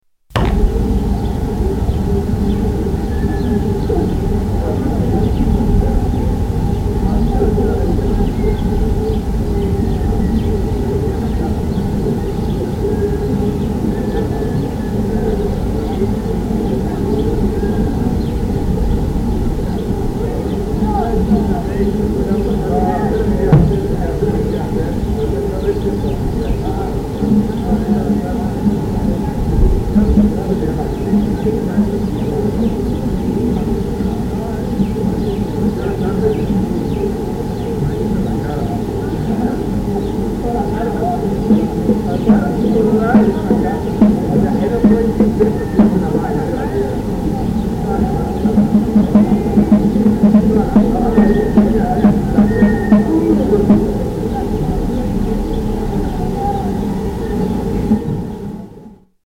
Purmamarca Square